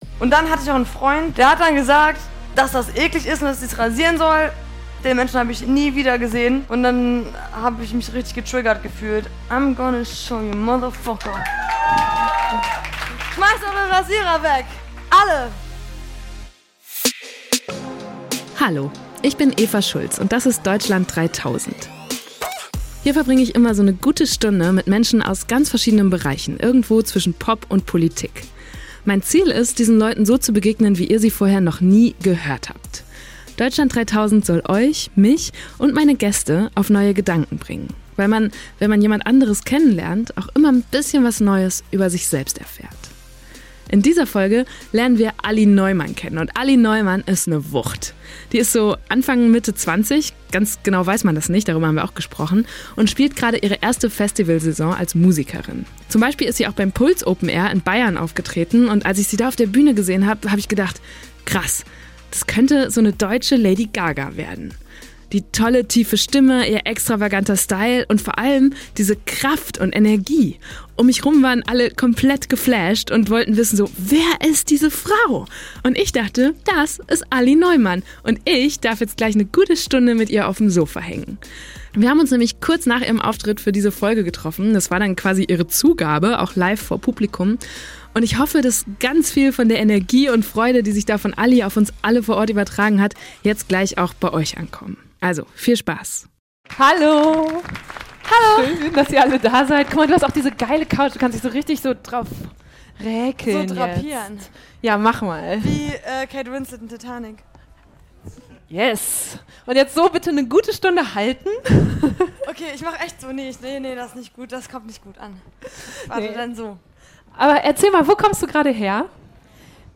Wir haben uns nämlich kurz nach ihrem Auftritt für diese Folge getroffen – das war quasi ihre Zugabe, auch live vor Publikum.